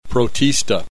click this icon to hear the preceding term pronounced protozoans and algae of various types